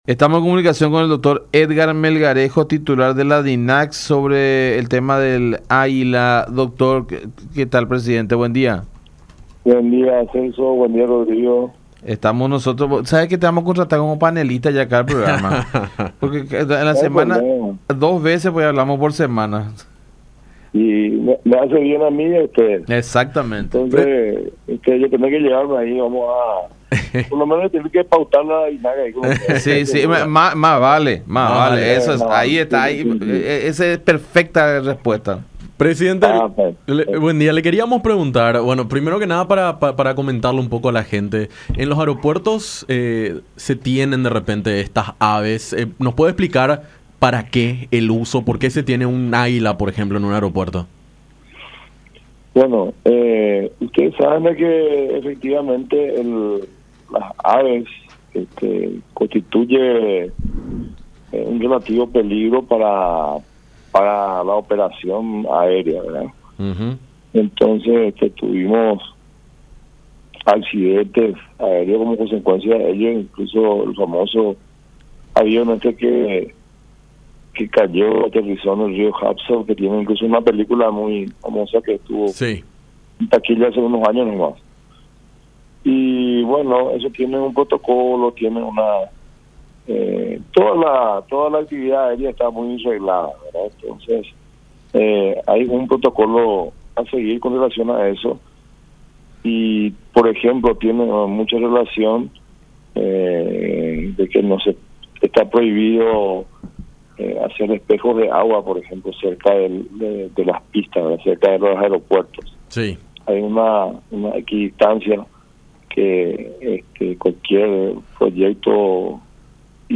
“Vamos a evaluar dentro del protocolo cuáles son las medidas reales de mitigación y su grado de importancia”, dijo en comunicación con La Unión, agregando que el servicio y su mantenimiento tenía un costo de G. 500 millones para el aeropuerto.